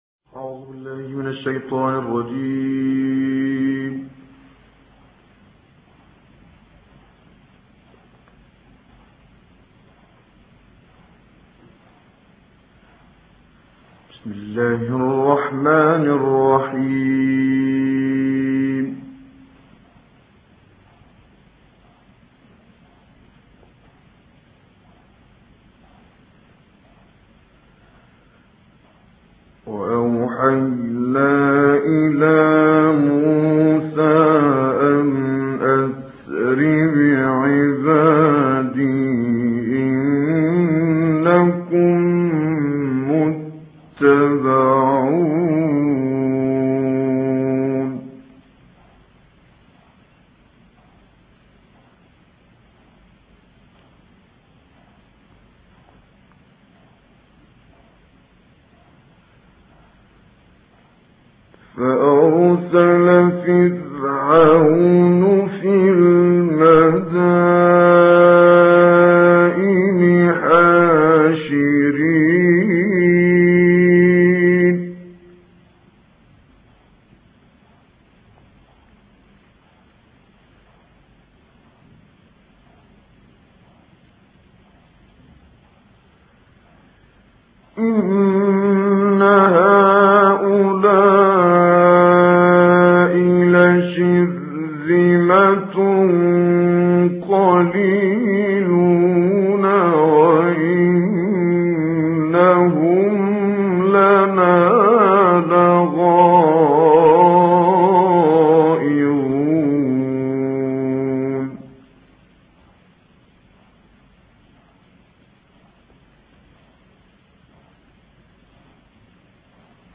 Quran recitations
Reciter Kamel Yousf El Behteemy